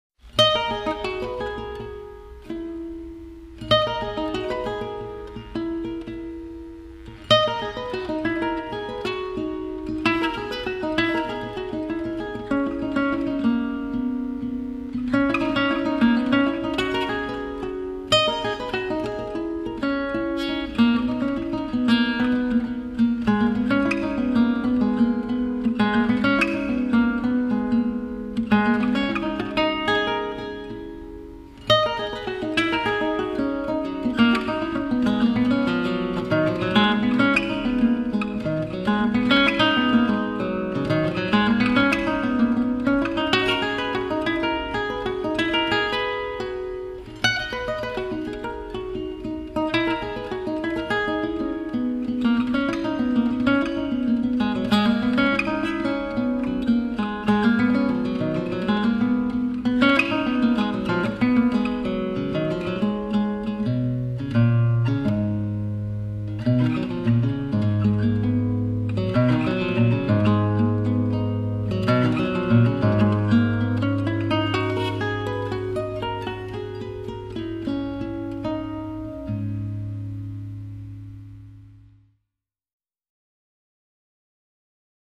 Original guitar music
Una improvisación que lleva a una idea que parece querer afirmarse…